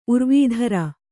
♪ urvīdhara